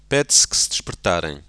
KAPITEL 4 - FONOLOGIENS GRUNDBEGREBER 4.1: portugisisk [ˈped̥sksd̥ʃpɾ̥ˈtaɾɐ̃j̃]